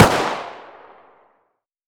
glock20_distance_fire1.wav